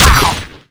sentry_shoot.wav